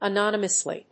音節a・nón・y・mous・ly 発音記号・読み方
/əˈnɒnəməsli(米国英語), ʌˈnɑ:nʌmʌsli:(英国英語)/